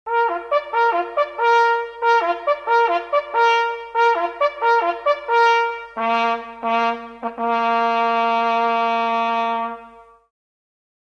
Jagdhornbläser
Wenn auch die Jagdhörner in der Zahl der zur Verfügung stehenden Töne begrenzt sind, so vermitteln sie doch mit ihren einfachen Melodienfolgen beim Zuhörer einen unvergleichlichen naturverbundenen Eindruck.